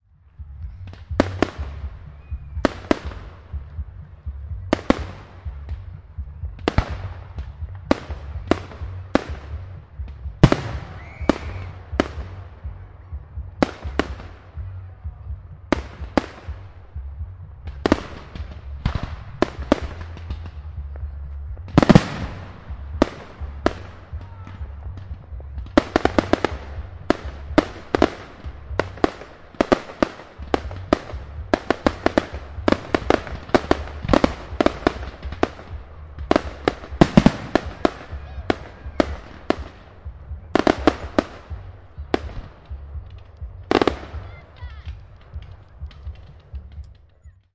酒吧音响
描述：当我在名为“Bina”的酒吧时，我录制了这个声音，每个人都在聊天。